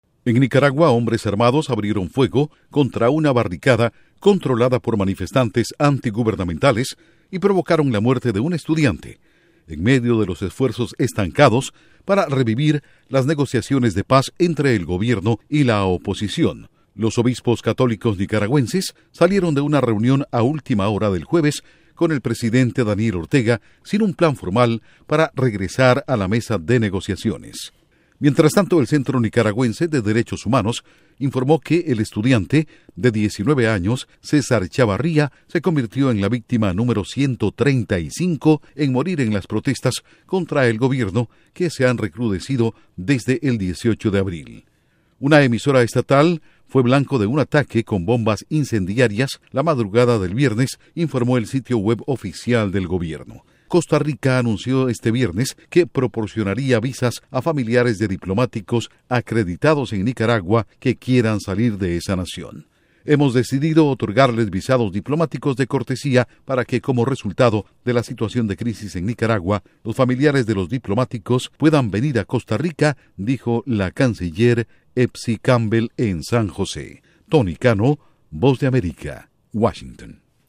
Muerte de otro estudiante en Nicaragua deja en suspenso conversaciones de paz. Informa desde la Voz de América en Washington